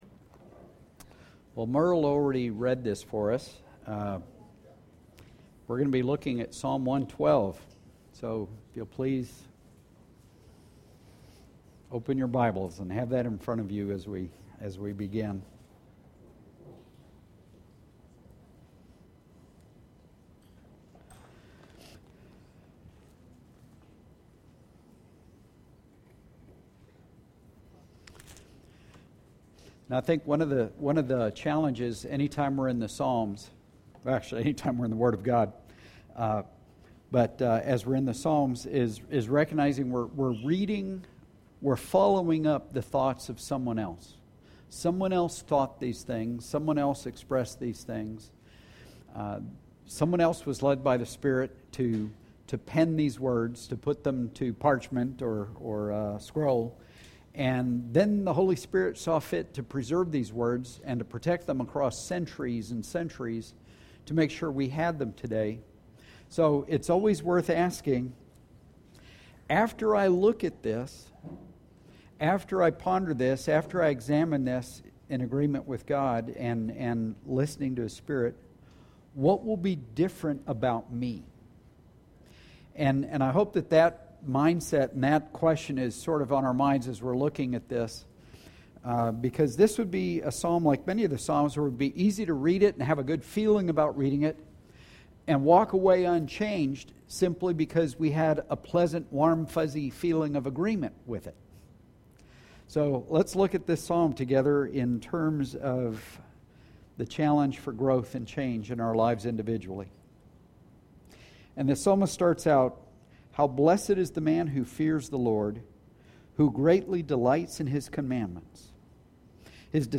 Editor’s Note: We had a problem about seven minutes in, and we lost some of the sermon. There is a pause where the error occurred, and it resumes a few seconds later where we were able to resume the recording.